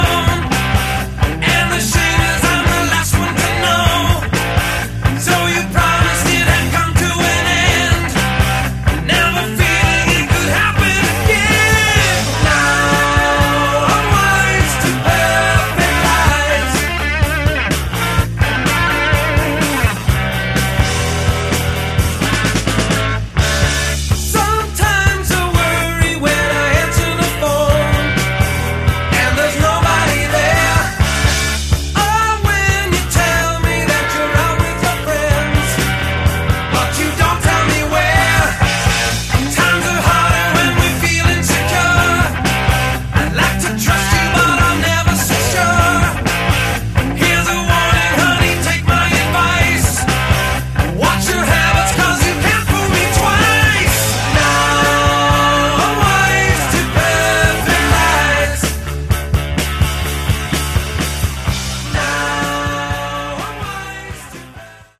Category: AOR
vocals, bass
keyboards, guitar
drums